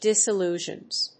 disillusions.mp3